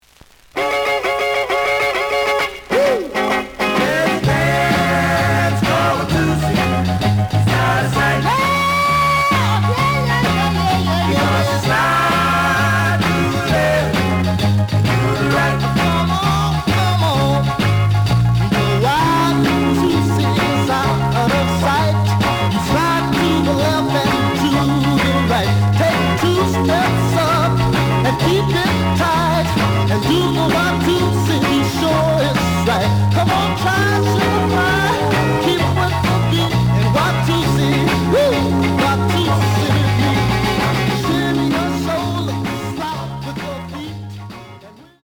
The audio sample is recorded from the actual item.
●Genre: Rhythm And Blues / Rock 'n' Roll
Some noise on A side.